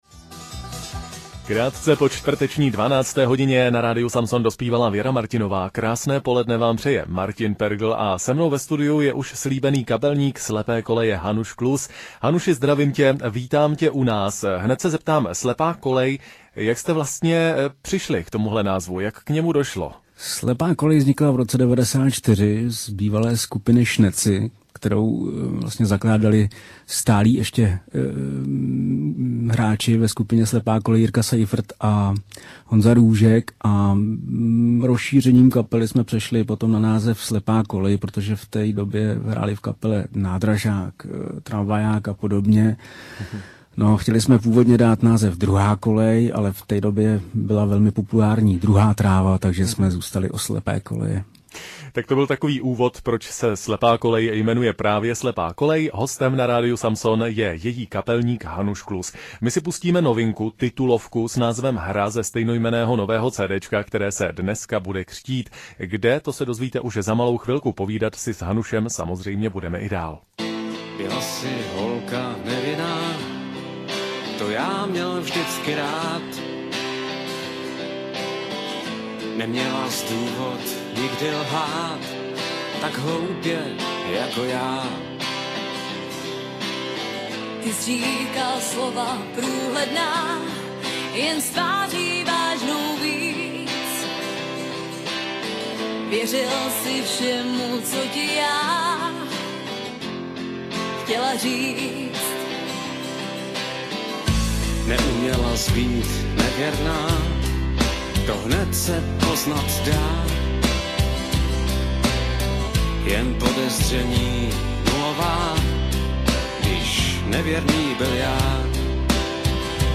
rozhovor_samson.mp3